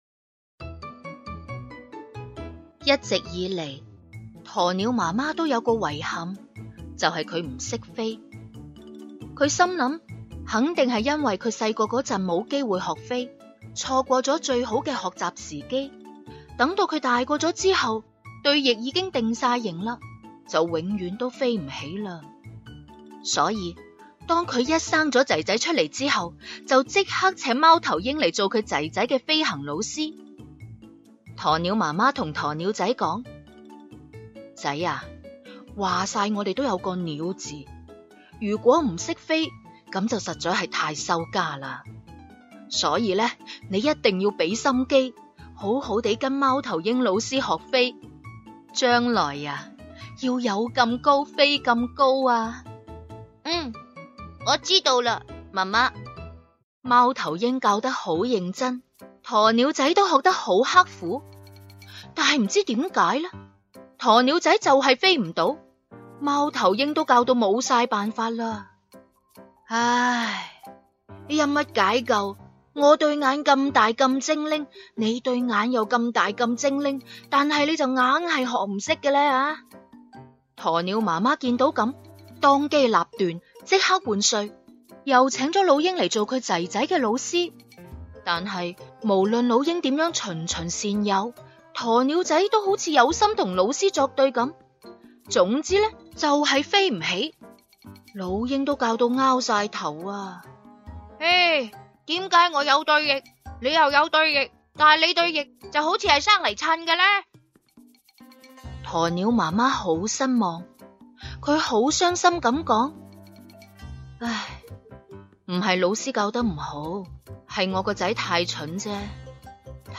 文章跟读练习